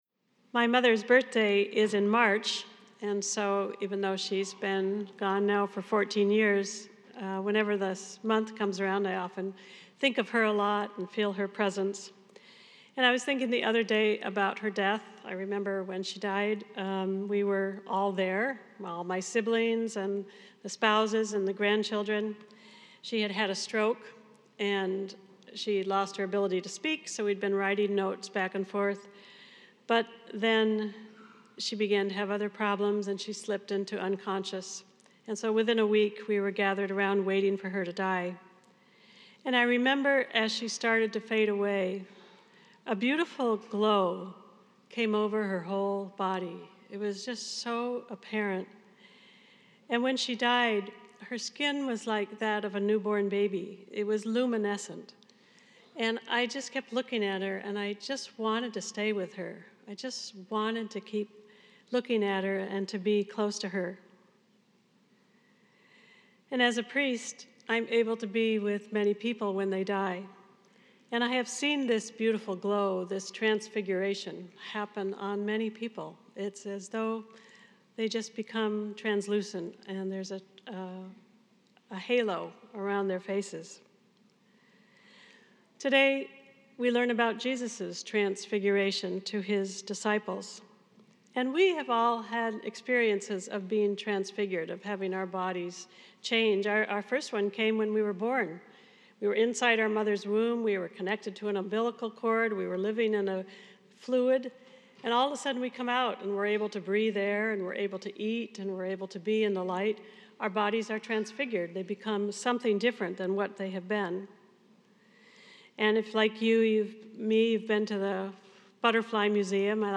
Homily Transcript My mother’s birthday is in March and even though she has been gone now for over 14 years, whenever this month comes around I think of her a lot and feel her presence.